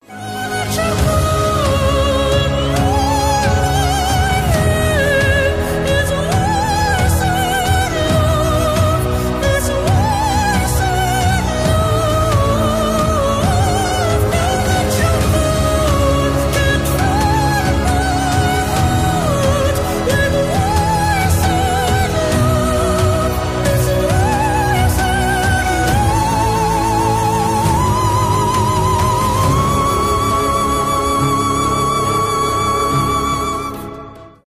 опера
сильный голос